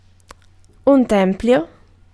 Ääntäminen
France: IPA: [tɑ̃pl]